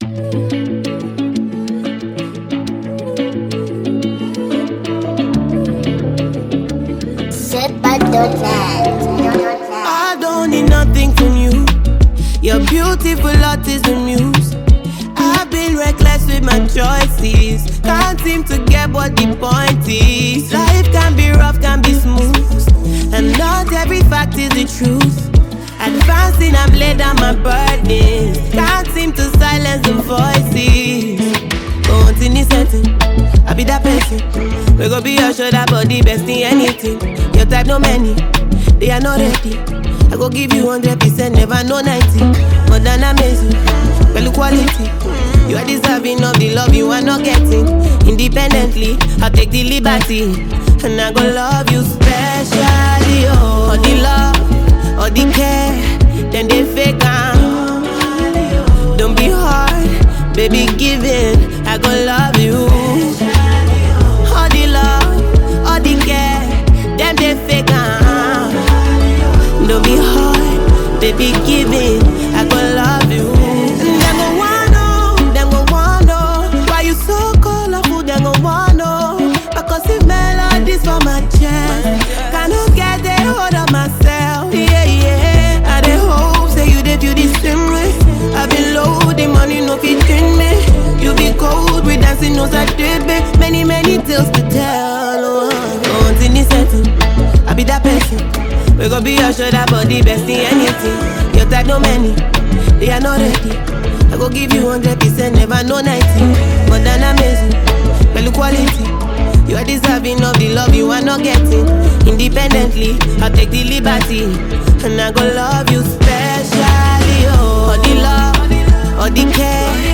Genre: Afrobeats
Category: Nigerian Music